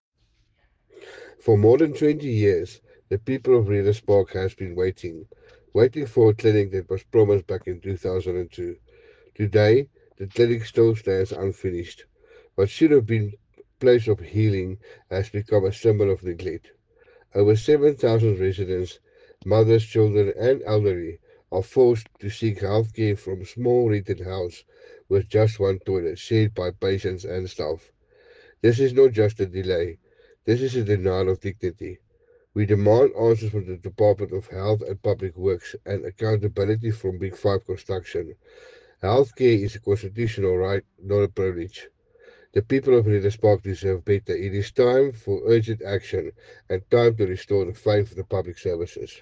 Afrikaans soundbites by Cllr Werner Janse van Rensburg and